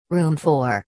It's all bytebeat, I love this stuff.